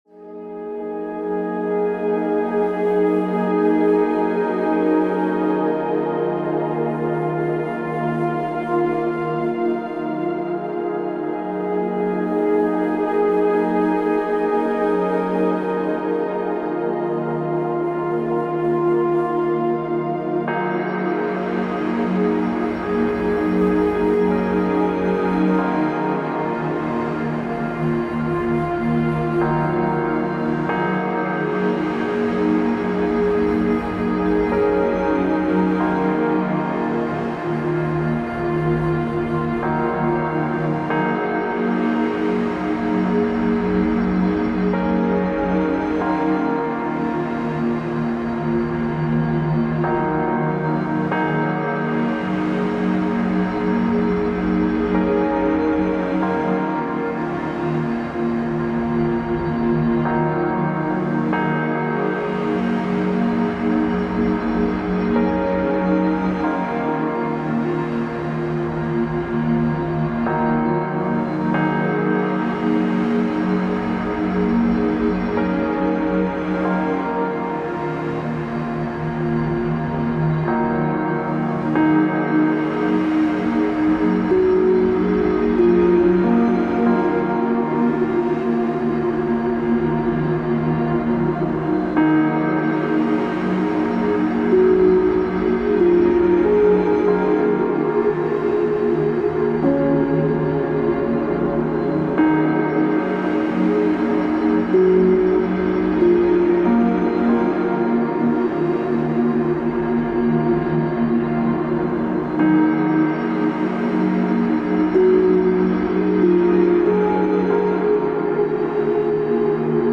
寂寥感漂うアンビエント楽曲で、淡々としたループと風音の上に残響を目立たせる意識で音を重ねている。